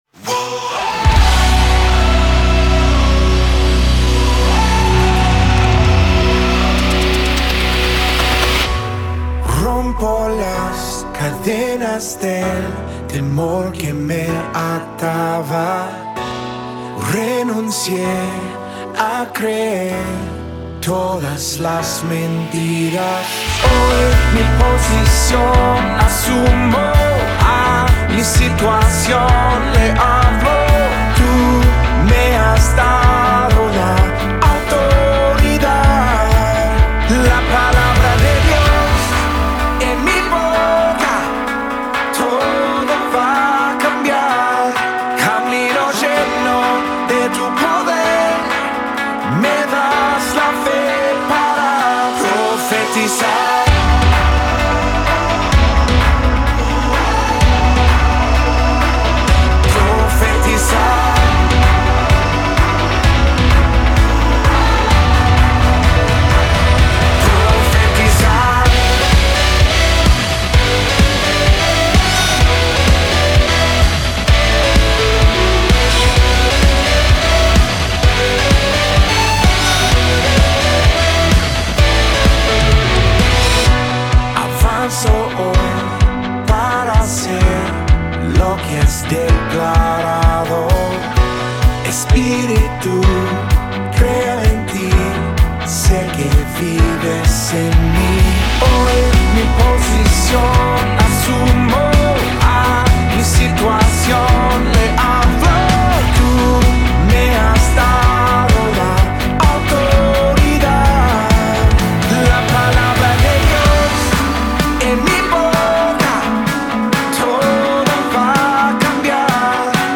Estrofa 1 -> pp +Voces + Piano
Interludio-> ff +Todos +MelodíaPiano
Coro -> pp +Voces +Piano +Guitar
Métrica: 4/4
Tempo: 128Bpm
Tonalidad: Bm o D / Original Cm